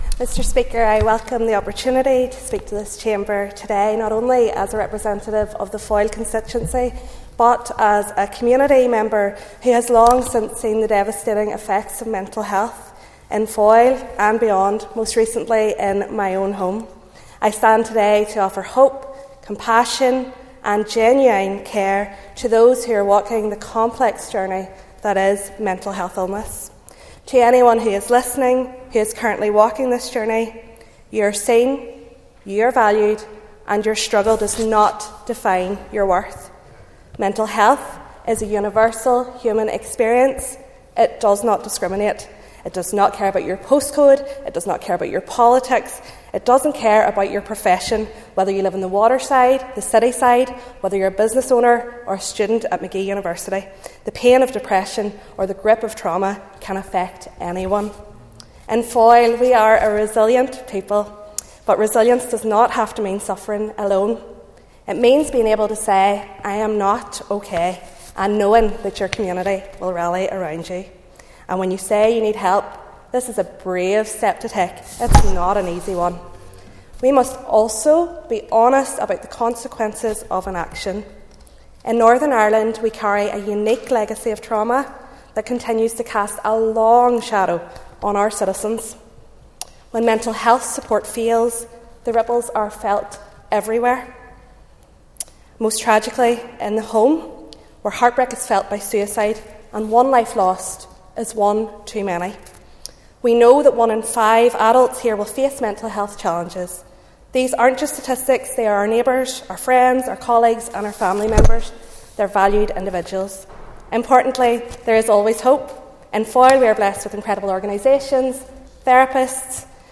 Middleton addresses mental health challenges in her maiden Assembly speech
Foyle MLA Julie Middleton has made her first speech in Assembly, using it to pay tribute to all those working in the provision of mental health services in the constituency, and urging anyone who is experiencing mental health issues to seek help.